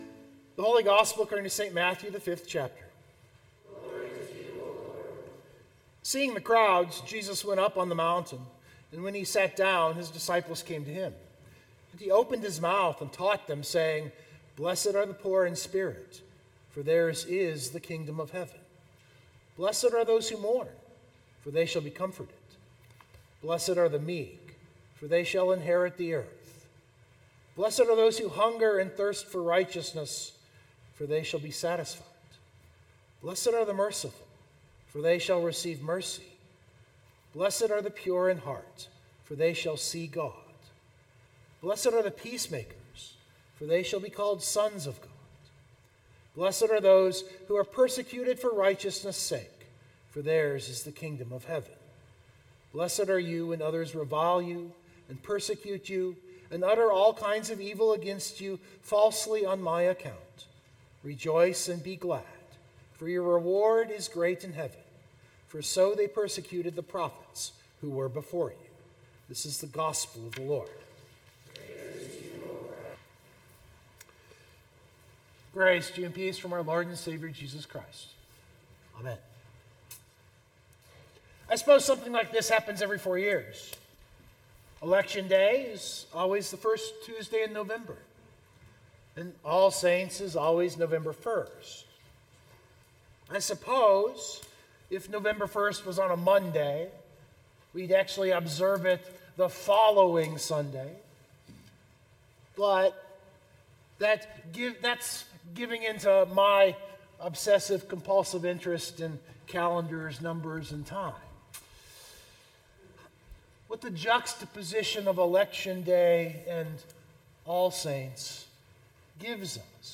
041325 Sermon Download Biblical Texts: John 12:12-19 (Palm Sunday), Luke 23:1-56 (Passion), John 18:28-38 (unread, but referenced) It was Palm Sunday also known as the Sunday of the Passion.